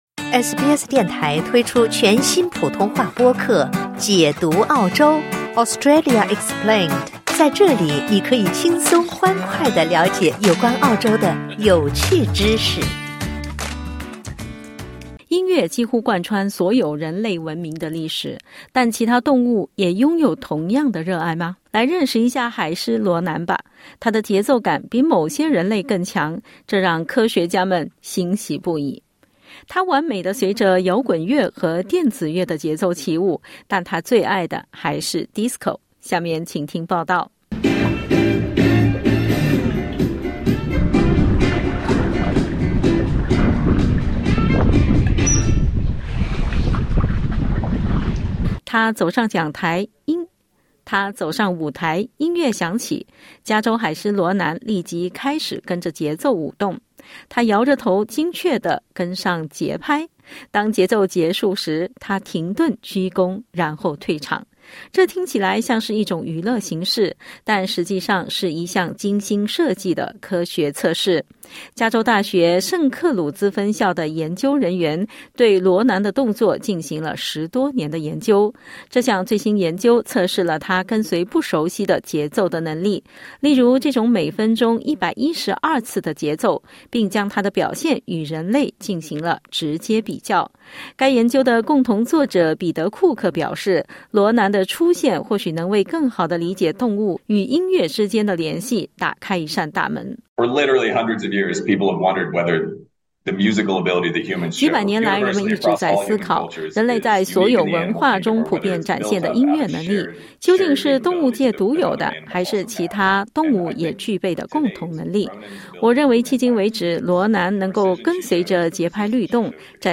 点击音频收听详细采访 欢迎下载应用程序SBS Audio，订阅Mandarin。